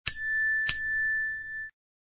SFX音效
SFX叮音效下载